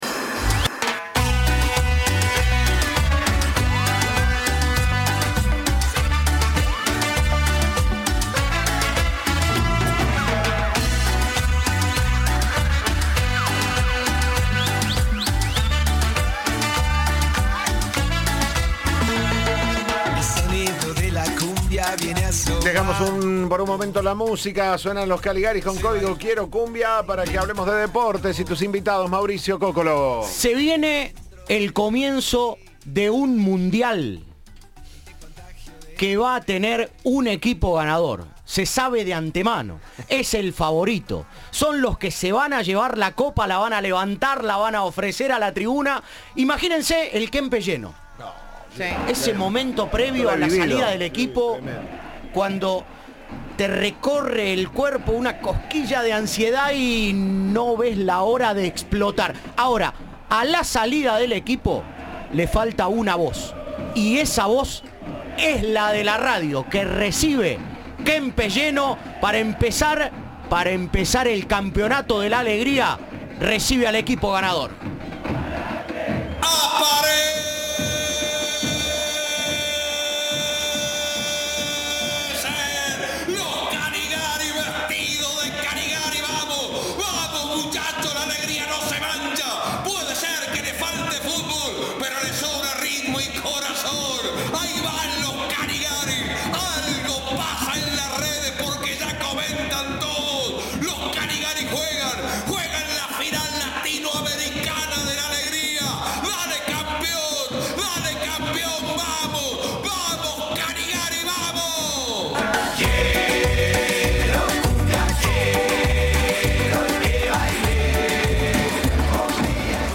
La banda cordobesa estuvo en los estudios de Cadena 3 para contar los detalles del nuevo lanzamiento.